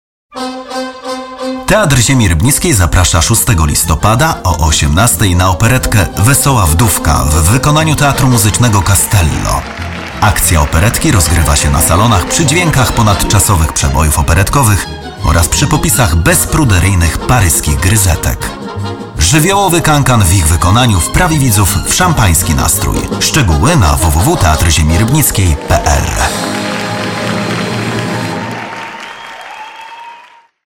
Male 30-50 lat
Voice artist with a young, charismatic voice timbre.
Udźwiękowiony spot reklamowy